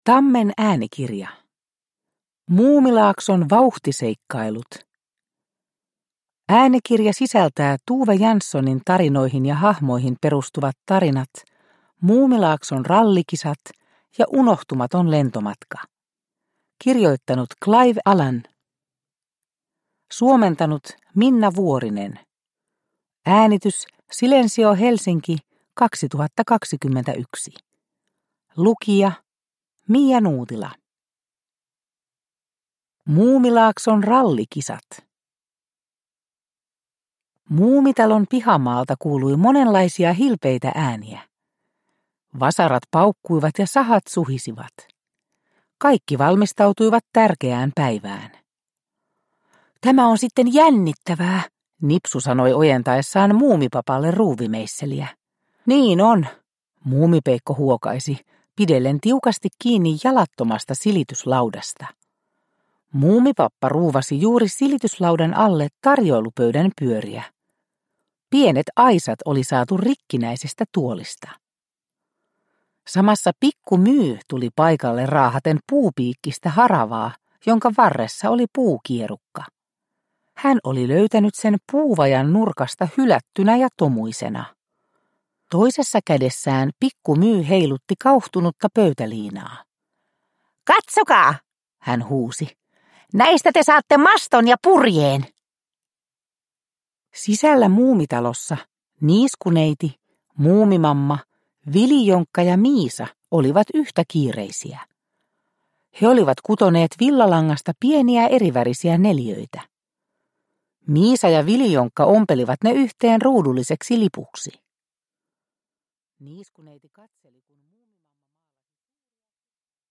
Muumilaakson vauhtiseikkailut – Ljudbok – Laddas ner